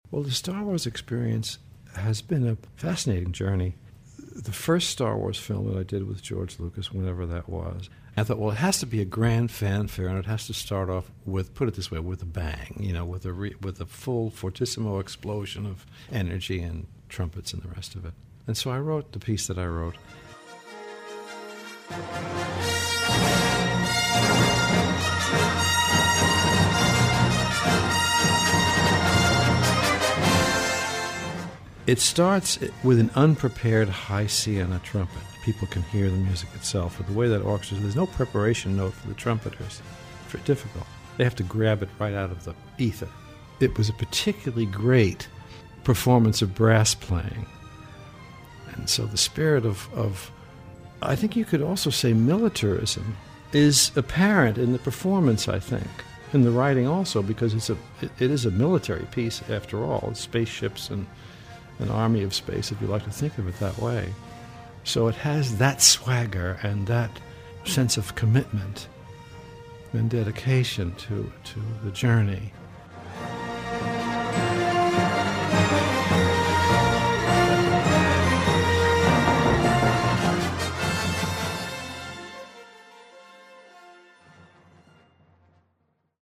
Transcript of conversation with John Williams